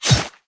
SwordHit.wav